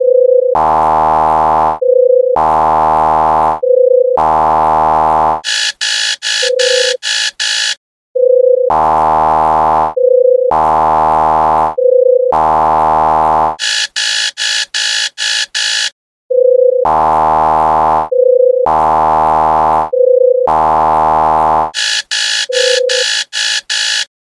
Kategorie Alarmowe